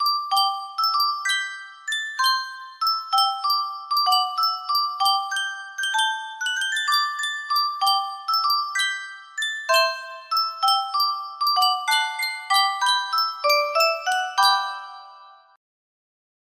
Yunsheng Music Box - The Rose of Tralee 6760 music box melody
Full range 60